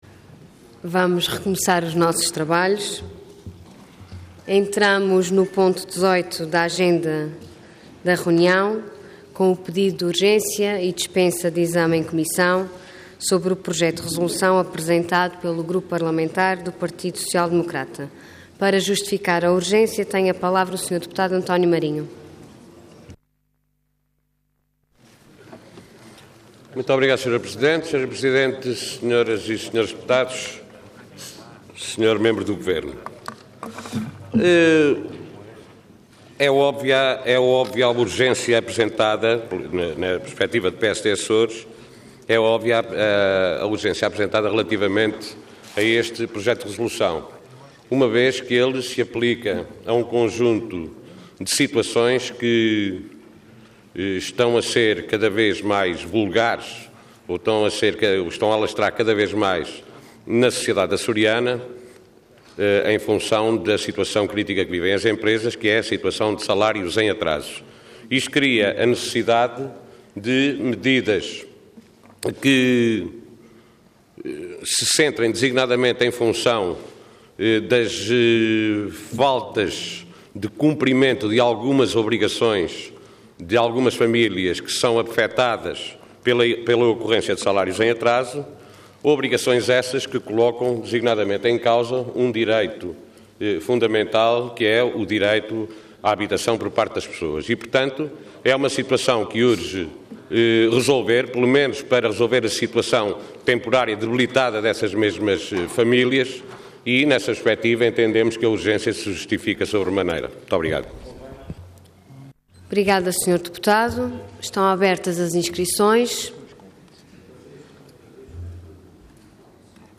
Website da Assembleia Legislativa da Região Autónoma dos Açores
Intervenção Pedido de urgência seguido de debate Orador António Marinho Cargo Deputado Entidade PSD